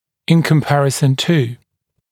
[ɪn kəm’pærɪsn tuː][ин кэм’пэрисн ту:]по сравнению с